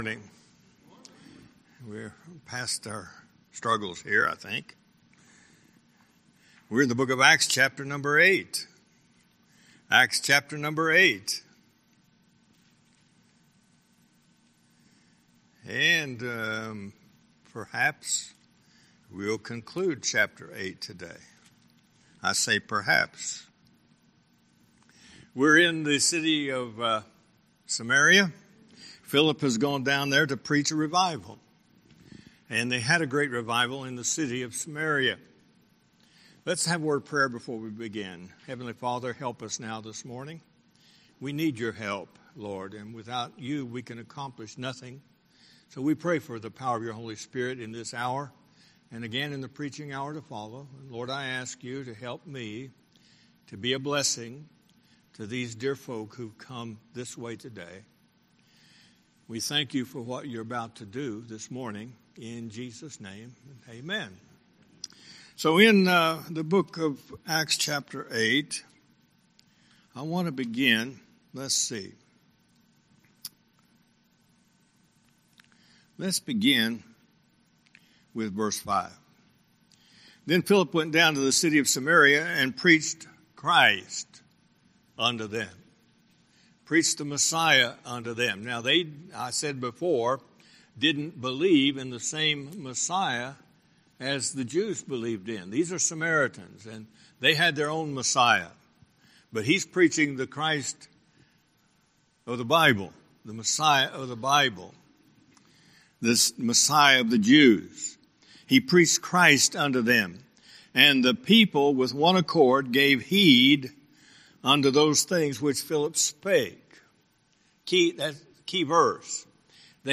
Sermons
Guest Speaker